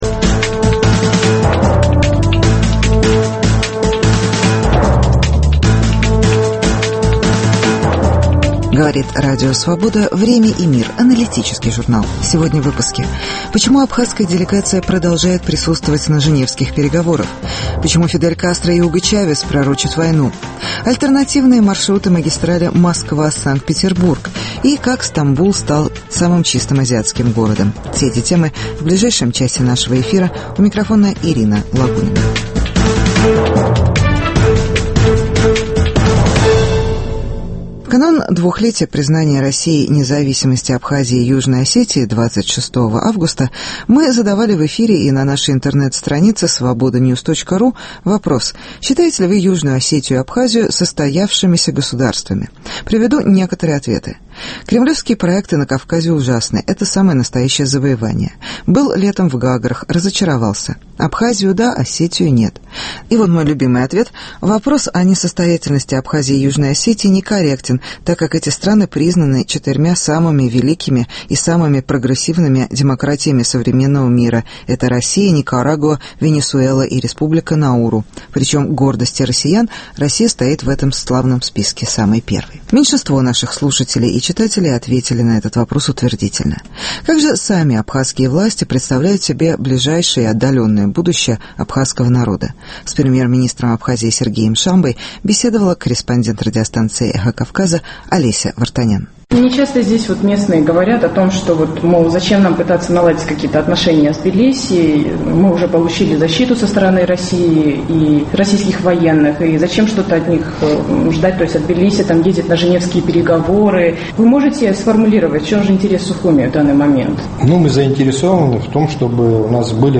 Интервью с Сергеем Шамбой. Почему Фидель Кастро и Уго Чавес пророчат войну?